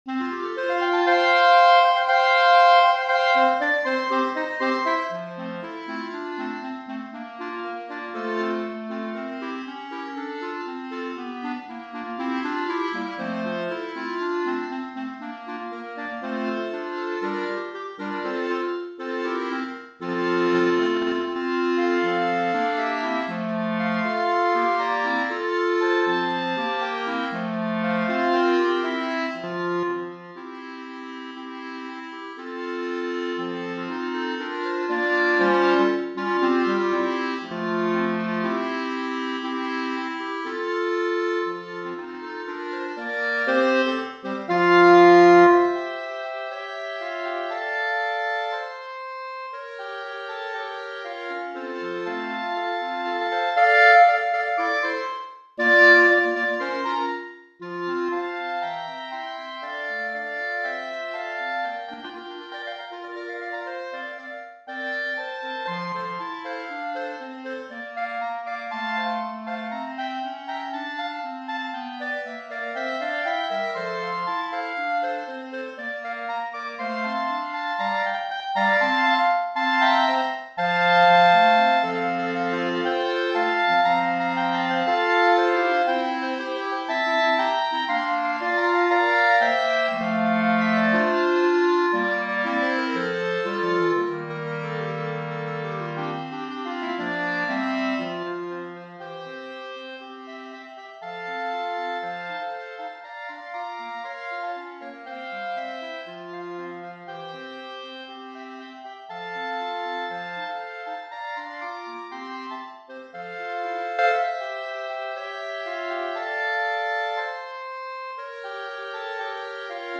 B♭ Clarinet 1 B♭ Clarinet 2 B♭ Clarinet 3 B♭ Clarinet 4
单簧管四重奏
可吹奏、可歌唱、热闹非凡！
变成了欢乐的单簧管重奏！请用轻盈和声的单簧管四重奏欣赏这人人都听过一次的可爱旋律。